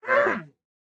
Minecraft Version Minecraft Version latest Latest Release | Latest Snapshot latest / assets / minecraft / sounds / mob / panda / cant_breed3.ogg Compare With Compare With Latest Release | Latest Snapshot
cant_breed3.ogg